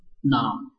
臺灣客語拼音學習網-客語聽讀拼-海陸腔-鼻尾韻
拼音查詢：【海陸腔】nong ~請點選不同聲調拼音聽聽看!(例字漢字部分屬參考性質)